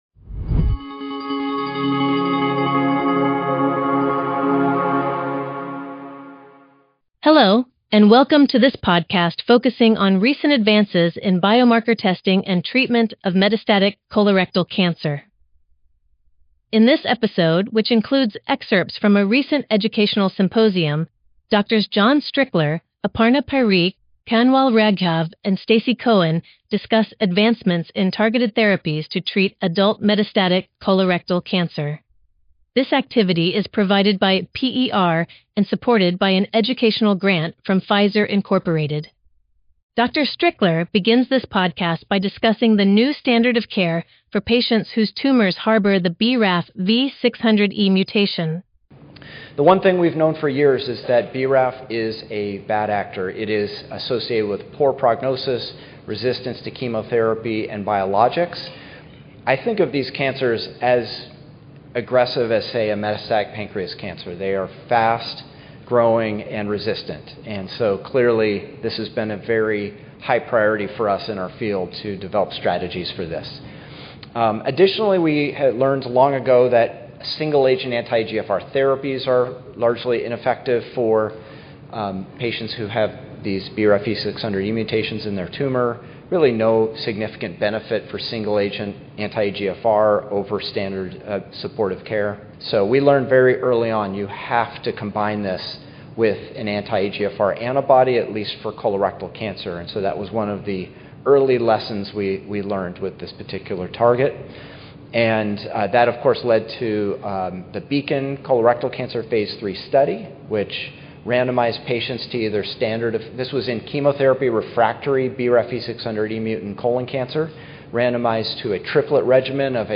GI Tumor Board